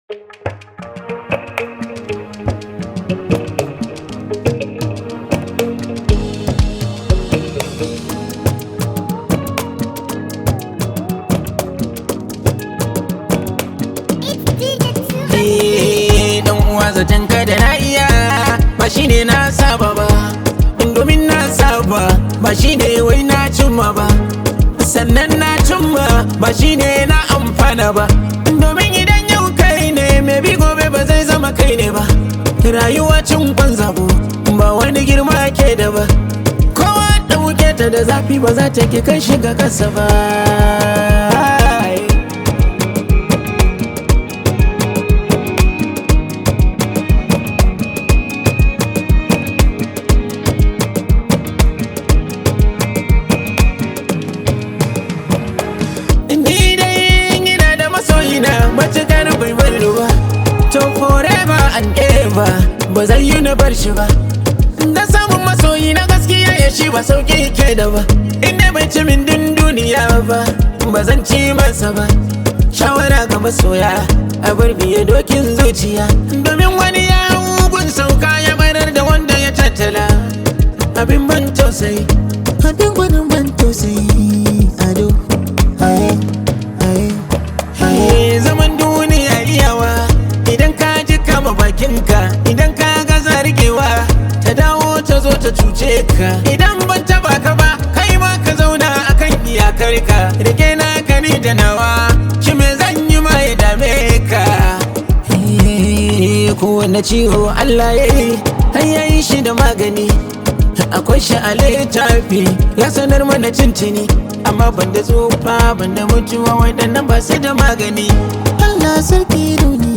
hausa song
high vibe hausa song